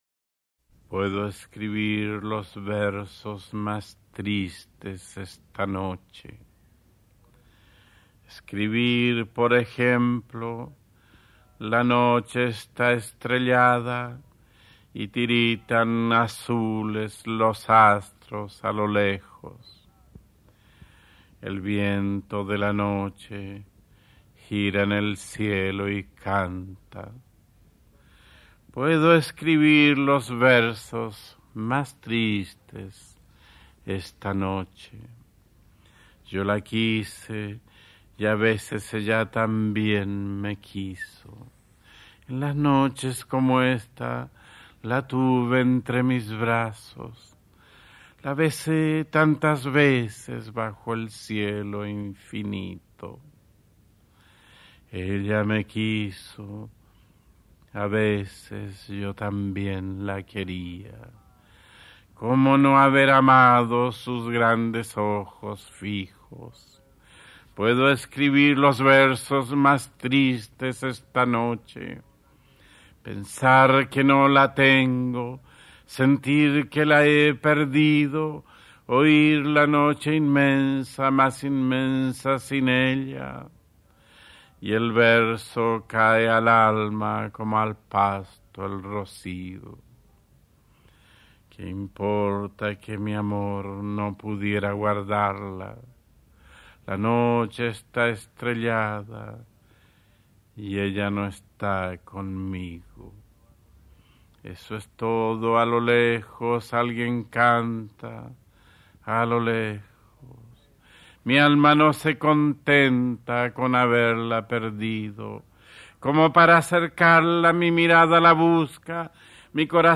Poema 20 - Voz de pablo Neruda